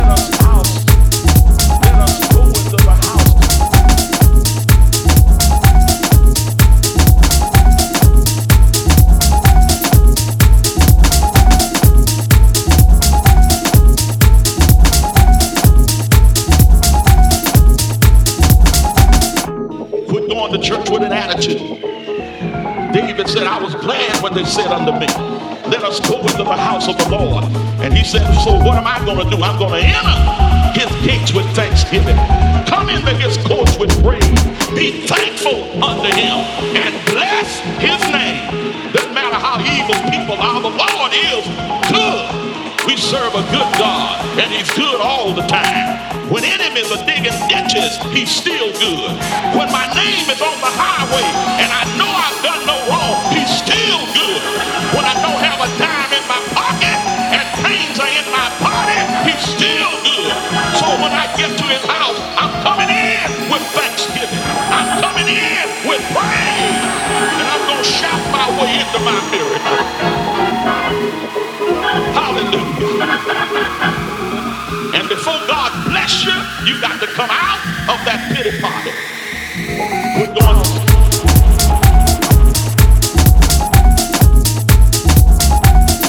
House tracks